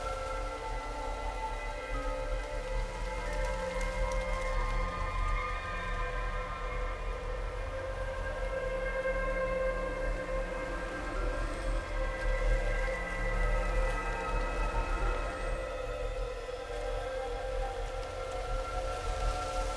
bloom_minigame_SFX_Amb_MainMenu.ogg